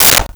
Plastic Bowl 01
Plastic Bowl 01.wav